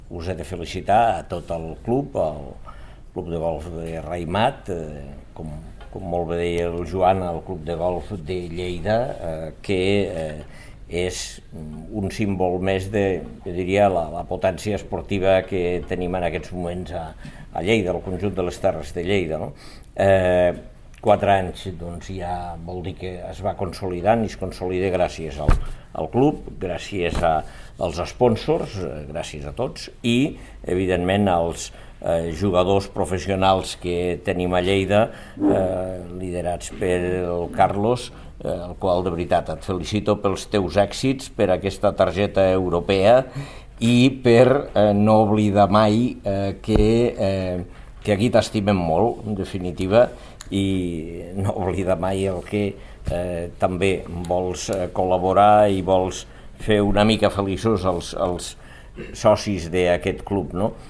Tall de veu d'Àngel Ros
tall-de-veu-dangel-ros-sobre-la-presentacio-del-torneig-del-raimat-golf-club